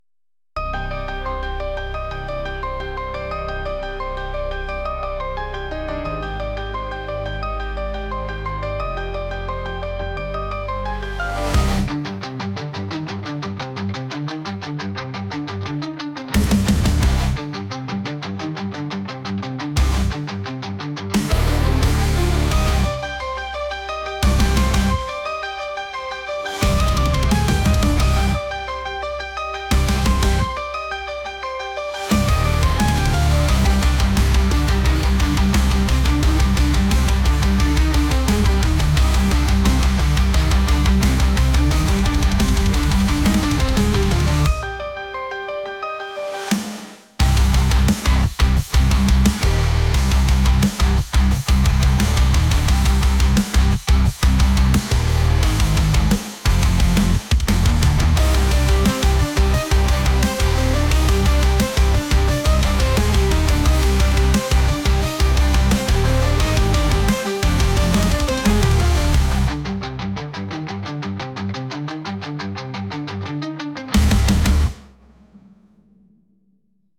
ピアノとギターのメタルチックでゲームのボス戦のような曲です。